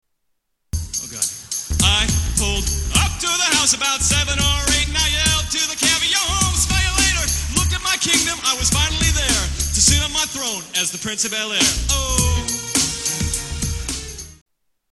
TV Theme Songs